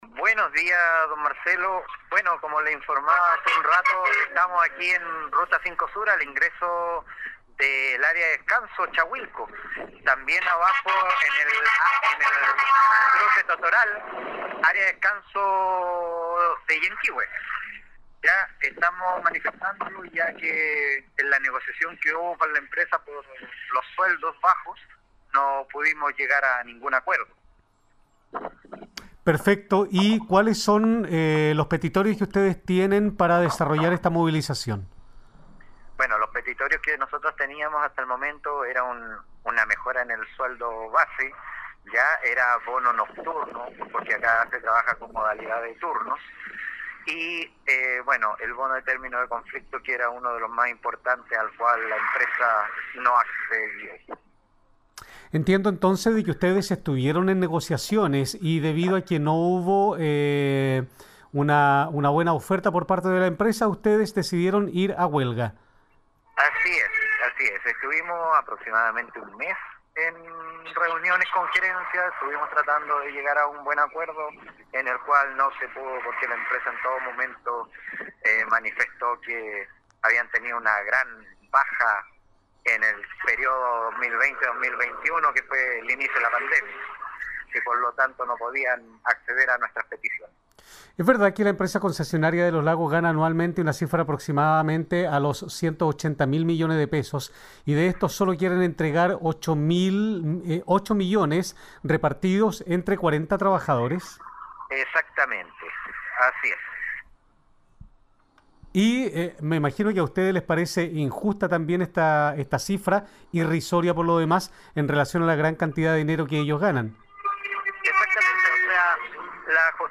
Entrevista con Radio Sago: